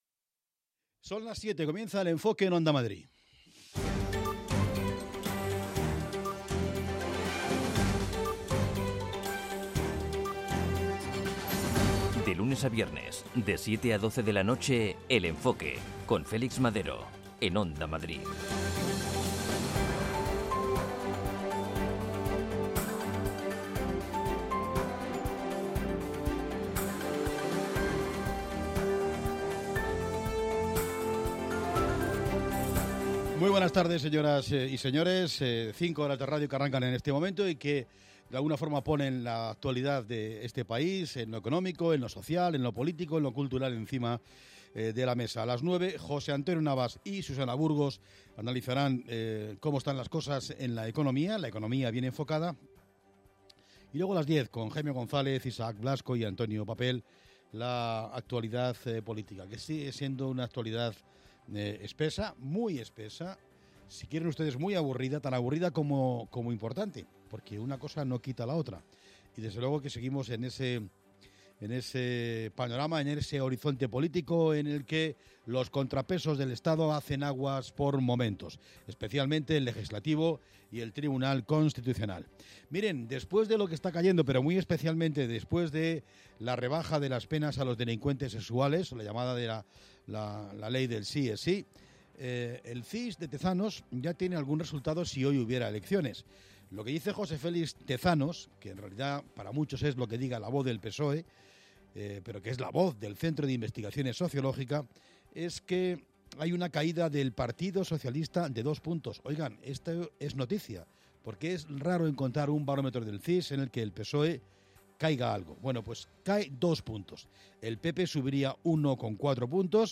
Cada tarde/noche a partir de las 19:00, Félix Madero da una vuelta a la actualidad, para contarte lo que ha pasado desde todos los puntos de vista. La información reposada, el análisis, y las voces del día constituyen el eje central de este programa, con la vista puesta en lo que pasará al día siguiente.